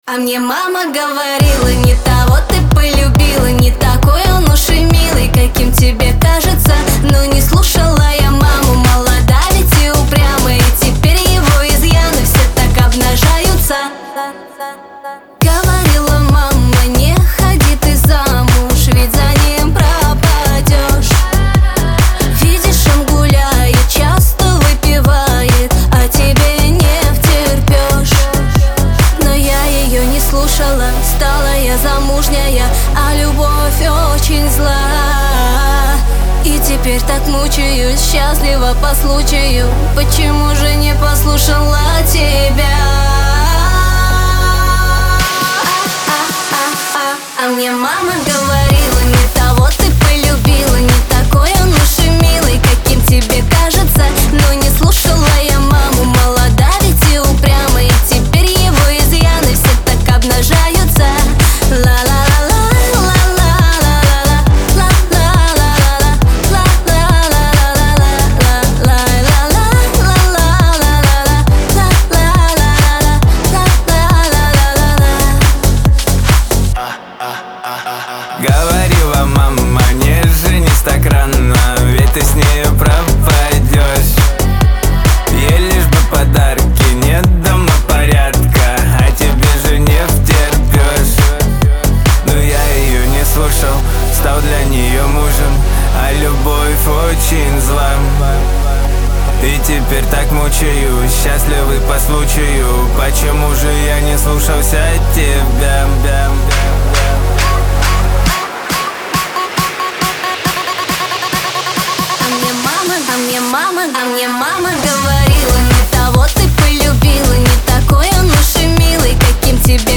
Лирика , Веселая музыка